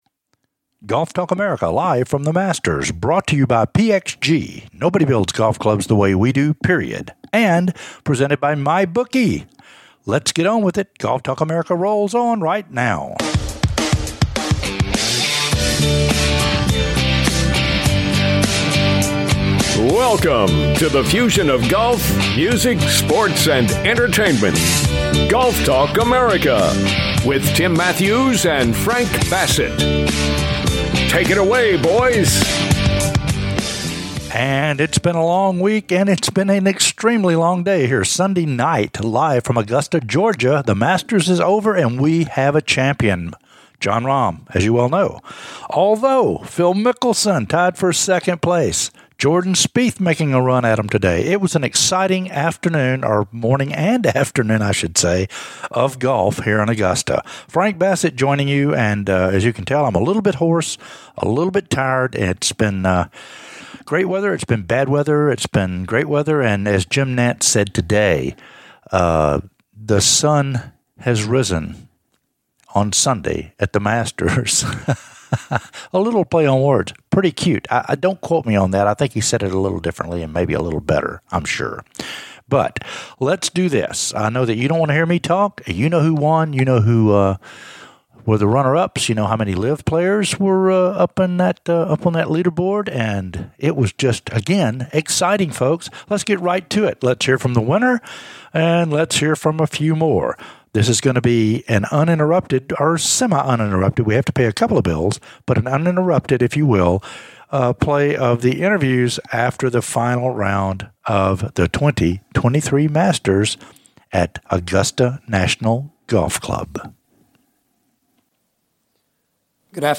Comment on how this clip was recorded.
THE 2023 MASTERS FINAL INTERVIEWS "LIVE FROM AUGUSTA"